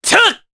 Kasel-Vox_Attack2_jp.wav